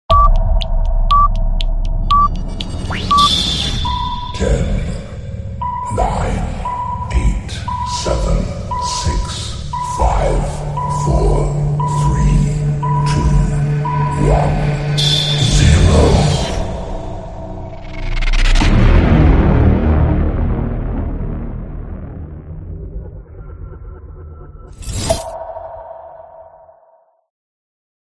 Play, download and share Countdown Scary original sound button!!!!
countdown-scary.mp3